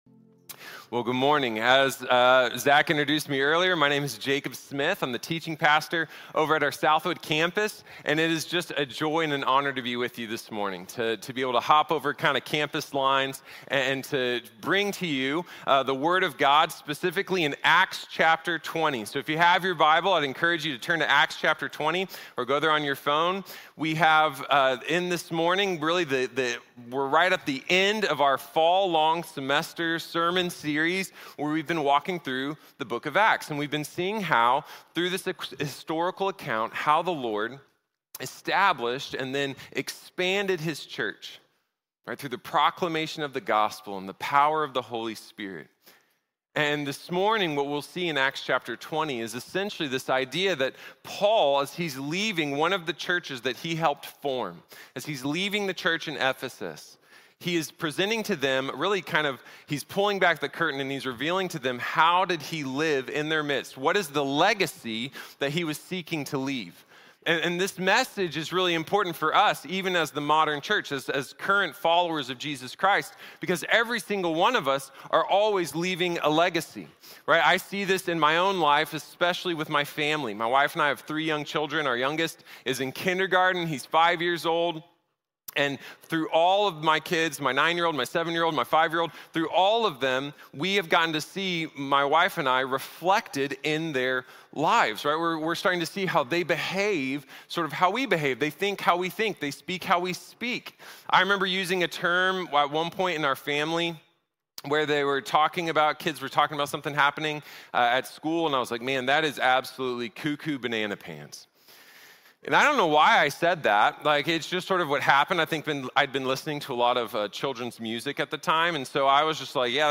Legado Perdurable | Sermón | Iglesia Bíblica de la Gracia